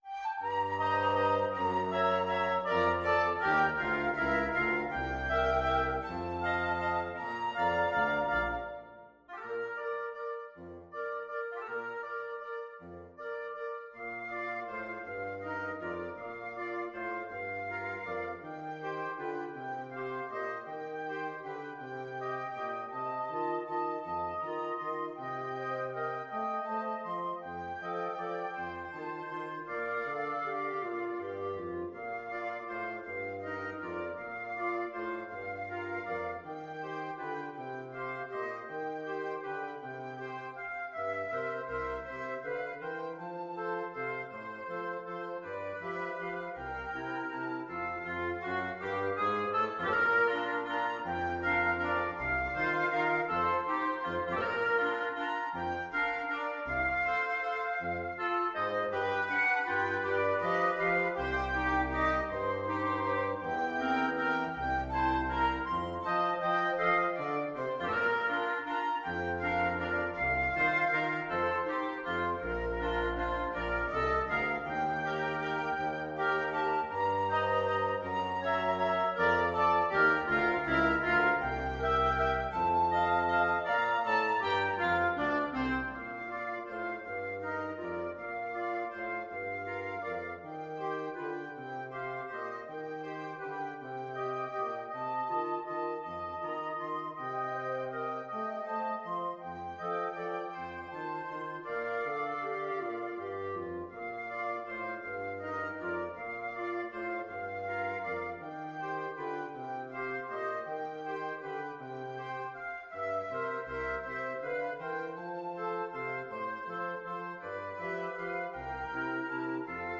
FluteOboeClarinetBassoon
= 160 Tempo di valse = c.120
3/4 (View more 3/4 Music)
Pop (View more Pop Wind Quartet Music)